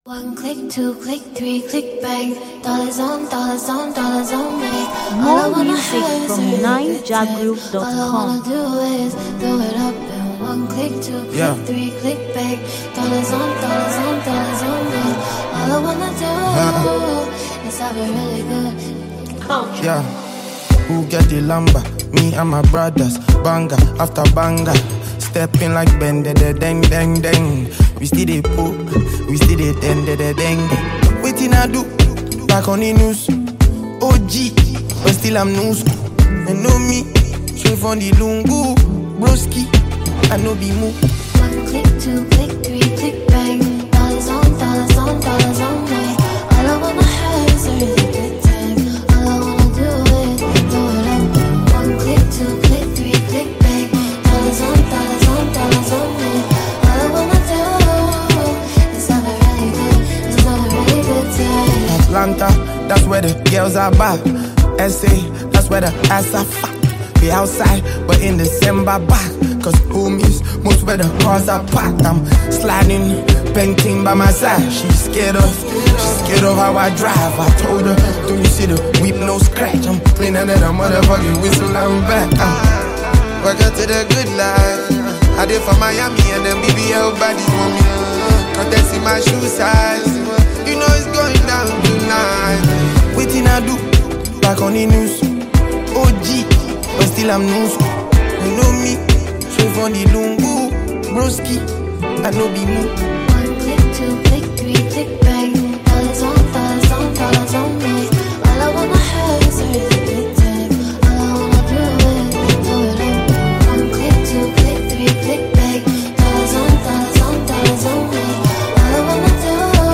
Nigerian singer and rapper